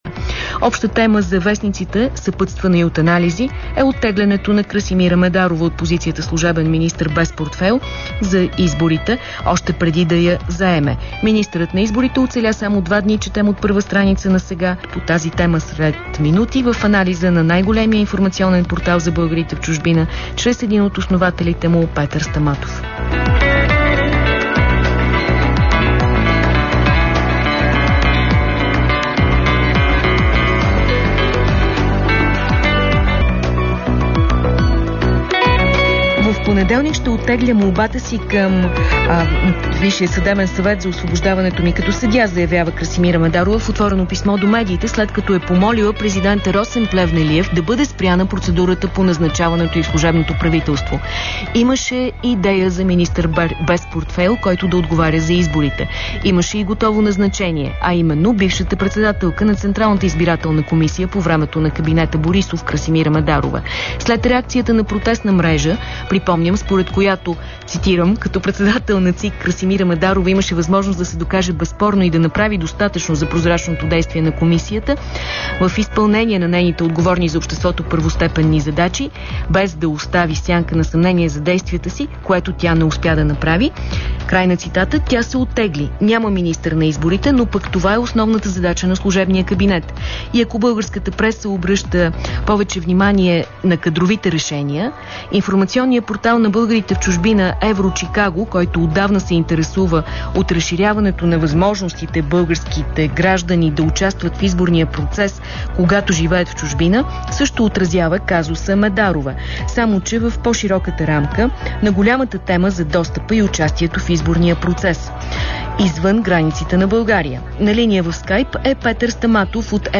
Чуйте запис на разговора им тук (кликнете с мишката върху триъгълничето в ляво).